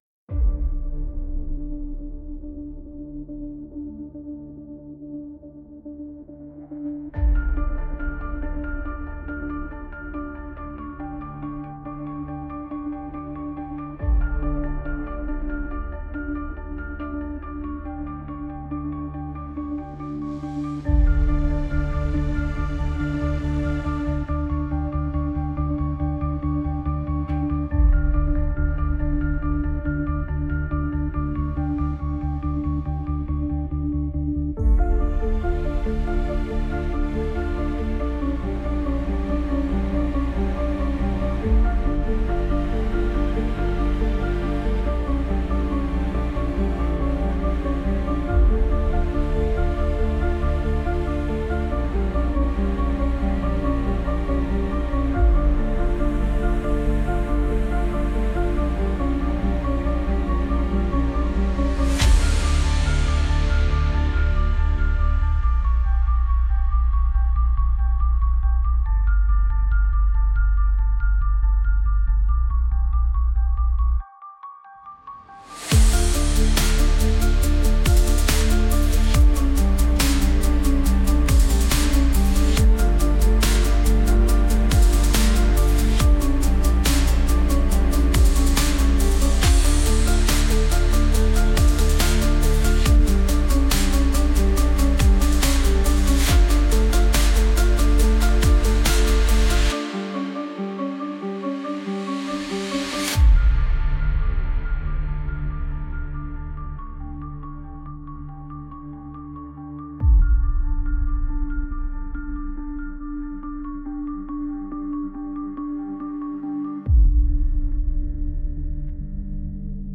Lost hope -dark background 3.43.mp3